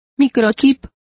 Complete with pronunciation of the translation of microchip.